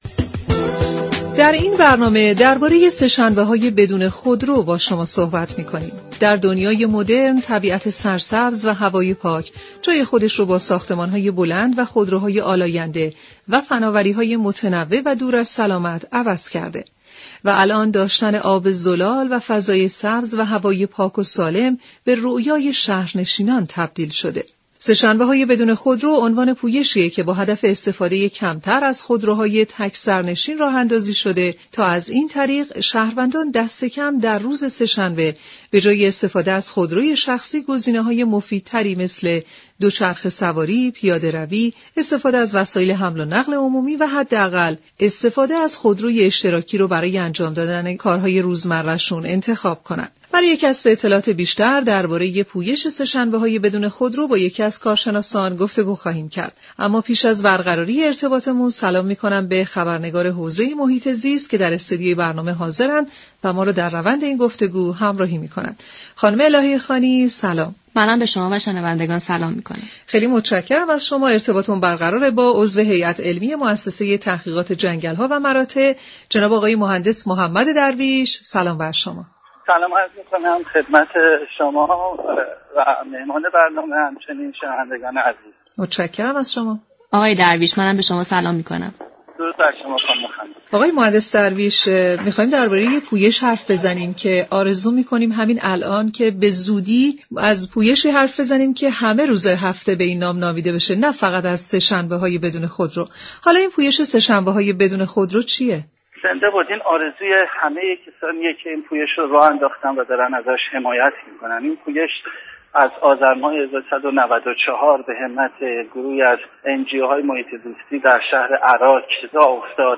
عضو هیات علمی موسسه تحقیقات جنگل ها و مراتع در گفت و گو با برنامه «سیاره آبی» گفت